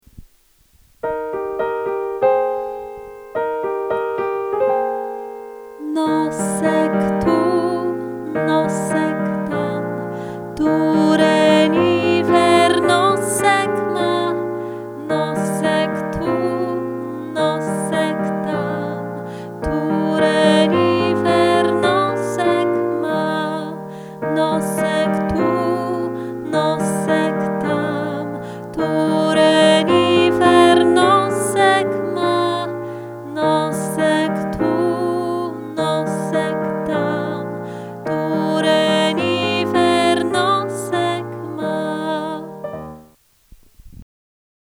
piano+wokal